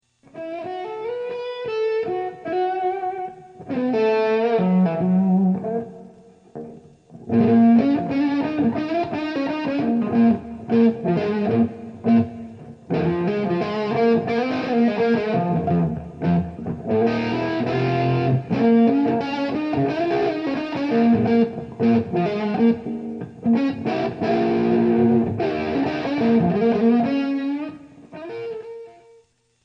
Elektrische gitaareffecten
Distortion -fuzz - overdrive - .
Uitgerust met 3 gain-matched germanium transistor voor een FAT, schroeiende leadtoon, heb ik ook de toonregeling respons en bereik bruikbaarder gemaakt en veel minder schel klinkend ... Vol met de klok mee is GLAD klinkend.
Dit pedaal heeft een tonaal karakter en zelfs harmonischen en natuurlijk wordt een echte bypass met LED bij elke pedaal geleverd.